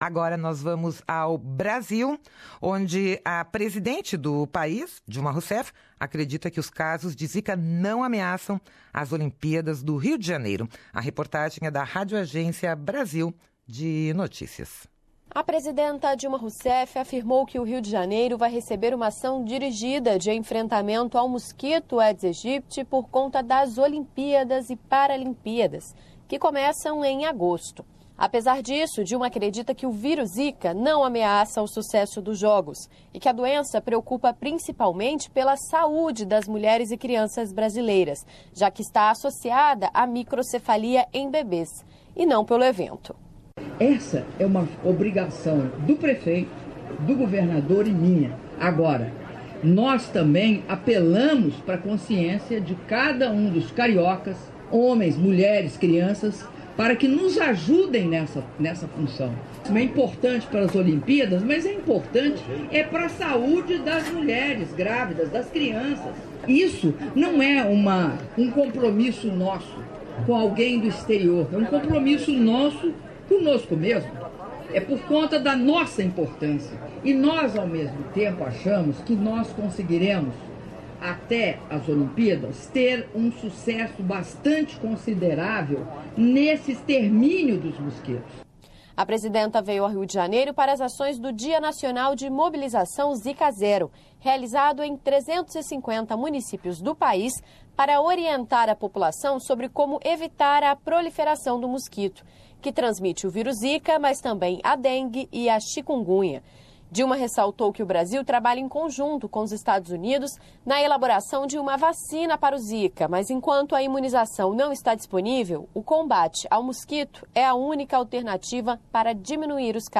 Nesta reportagem ouça, além da presidente do Brasil, também a mensagem em áudio da campanha maciça do governo federal de combate ao mosquito Aedes Aegypti.